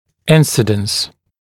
[‘ɪnsɪdəns][‘инсидэнс]охват, степень, процент, доля